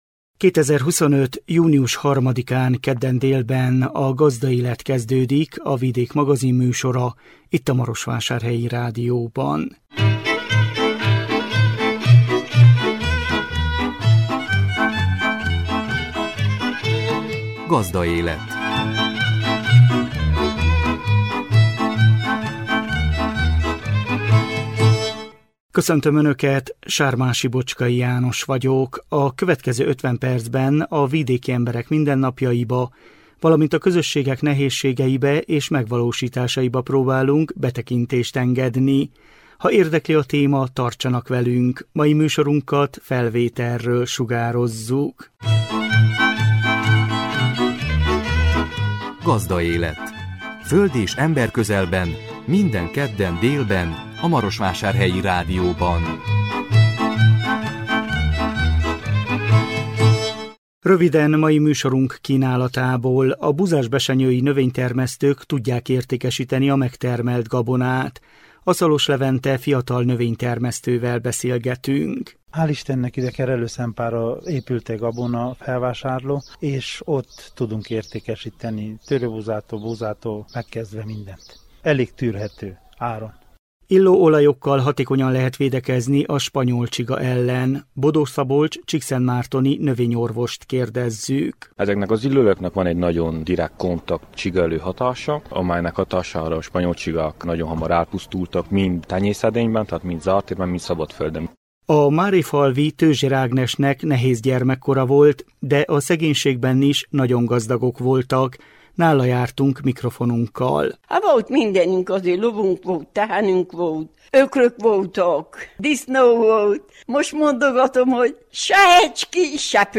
Nála jártunk mikrofonunkkal.